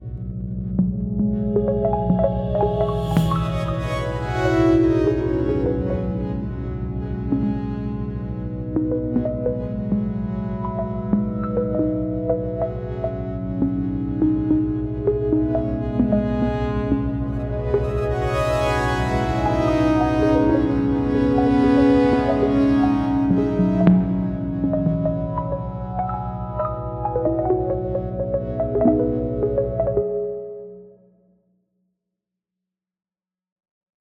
Sonification is a process that translates data from telescopes into sounds.
Meanwhile Hubble Space Telescope data reveal the galaxy’s spiral arms and background stars as low drone sounds and soft plucks and cymbals.